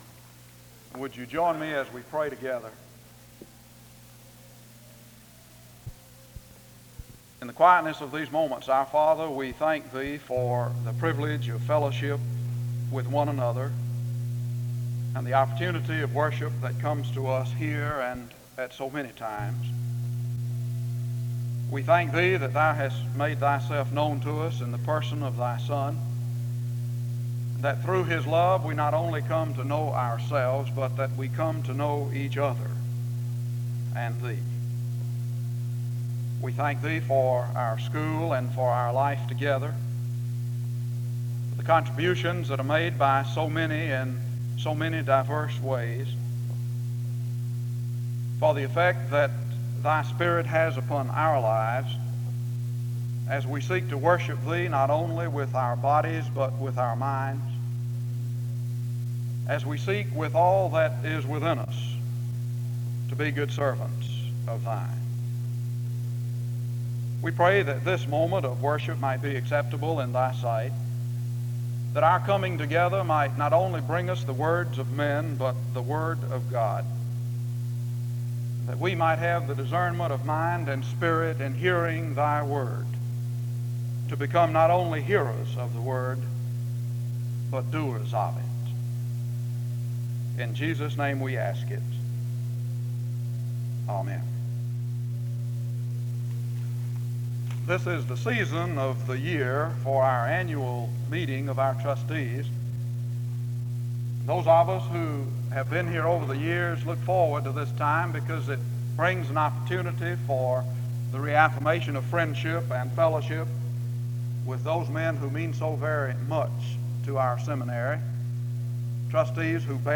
The service begins with a prayer (0:00-1:35).
The service continues with a period of singing (3:16-6:01).
He concludes by challenging his audience to “build Christian homes” (17:13-23:22). The service closes in prayer (23:23-24:14).